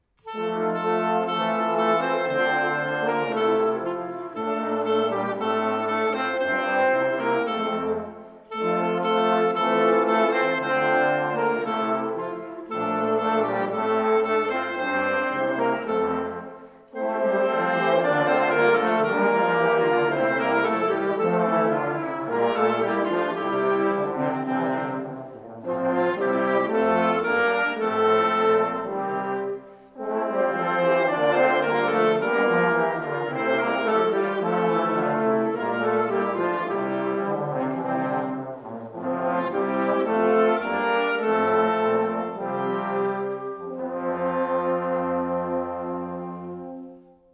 DJO spielt weihnachtliche Lieder
Das Detmolder Jugendorchester hat für uns einige weihnachtliche Lieder eingespielt.